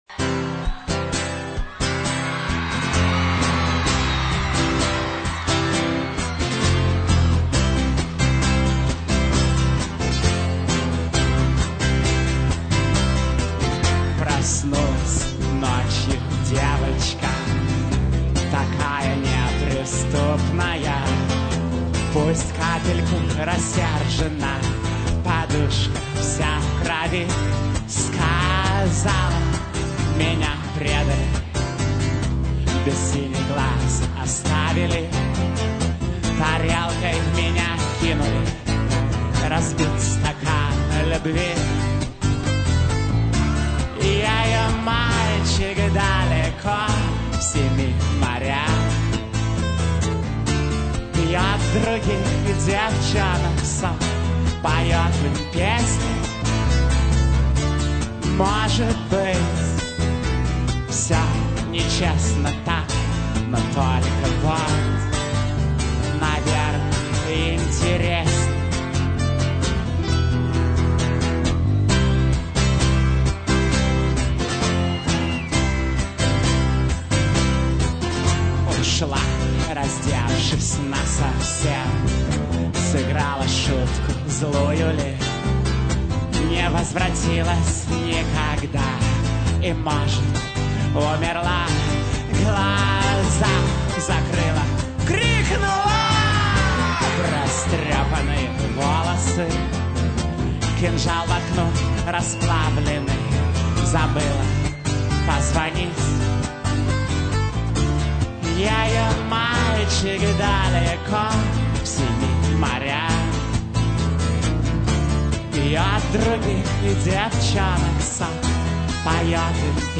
вокал, гитара
ударные, перкуссия, гитара